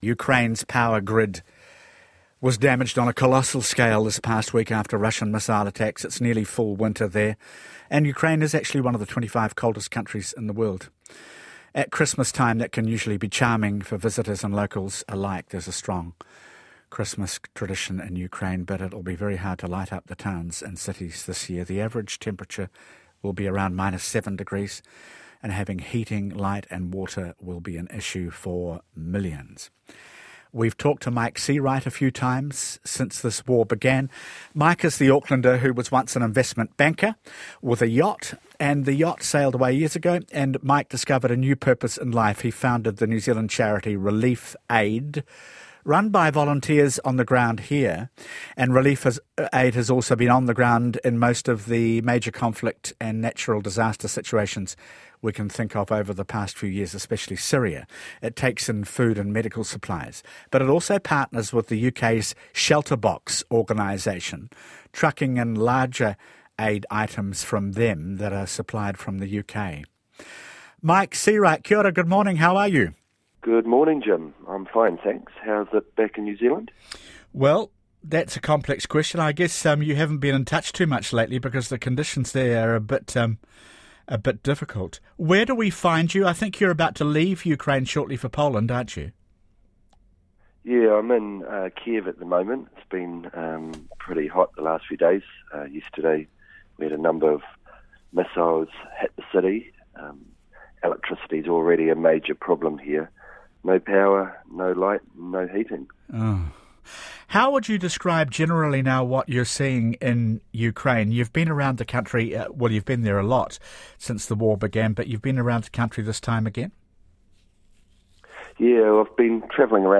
speaks on RNZ Sunday Morning